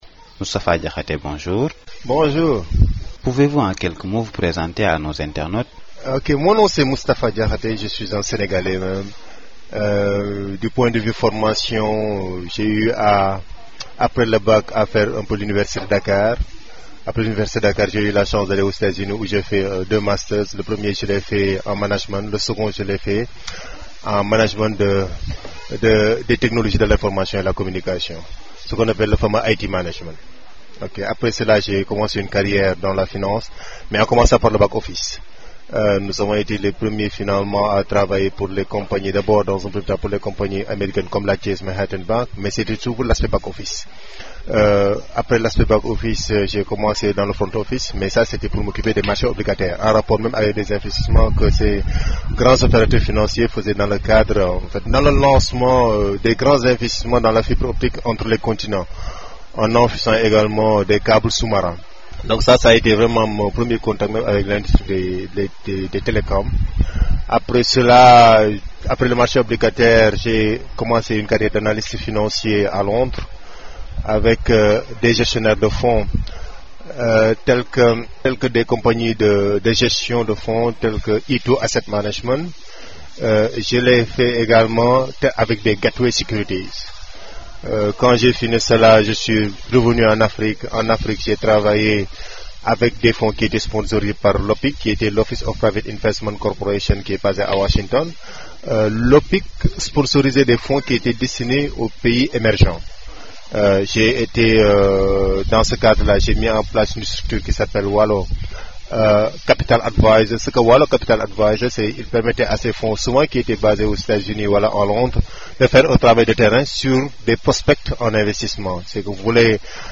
Nous vous livrons également cet entretien sous format audio.